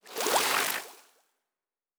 Nature Spell 22.wav